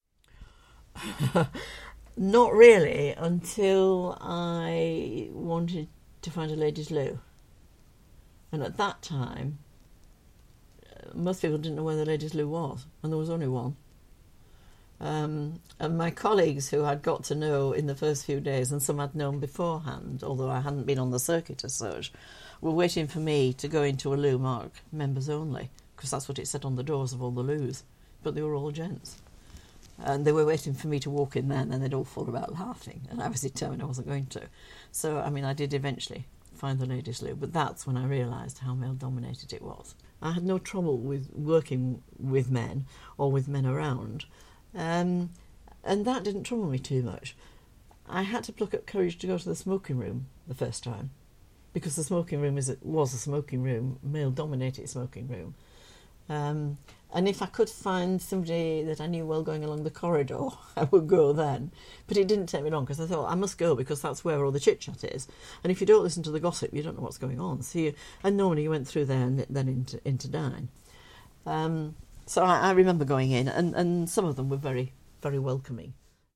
Her fellow Conservative MP Elizabeth Peacock (1983-1997) had this response to the question: ‘Were you conscious that you were entering quite a male-dominated world?’: